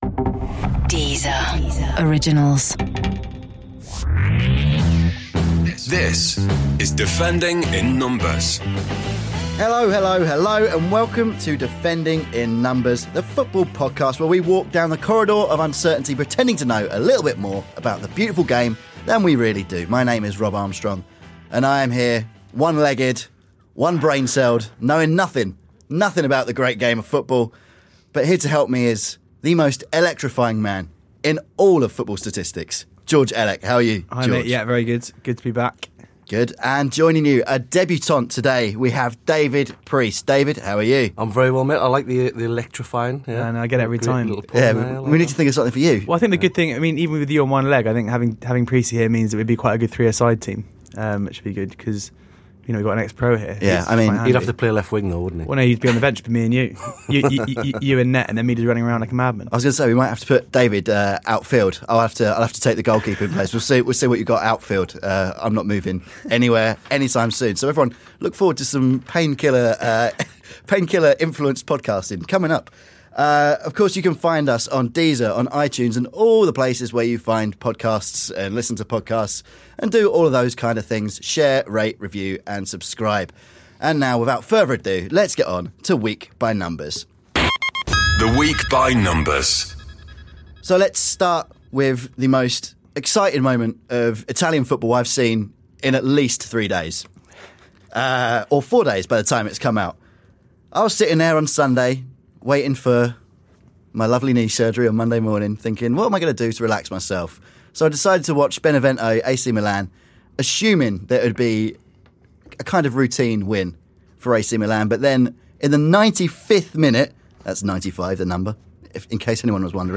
joined in the studio